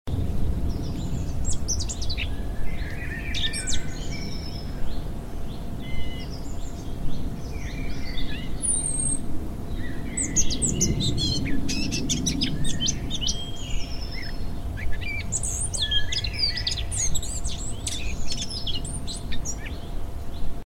Tonos gratis para tu telefono – NUEVOS EFECTOS DE SONIDO DE AMBIENTE de DIA SOLEADO SUNNY DAY
Ambient sound effects
dia_soleado_Sunny_Day.mp3